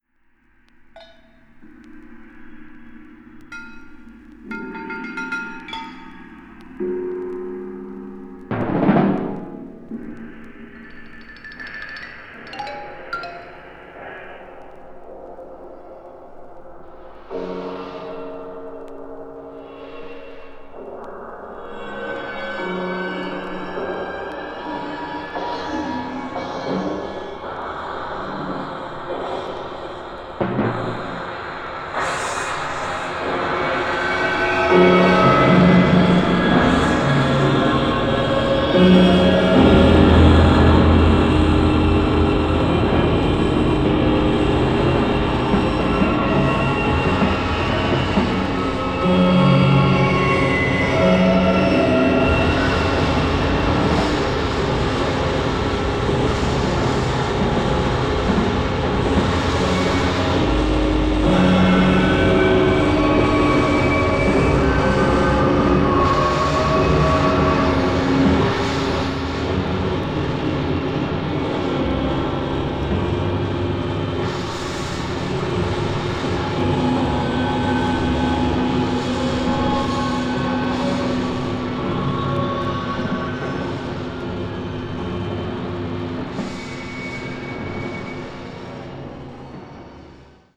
20th century   avant-garde   contemporary   post modern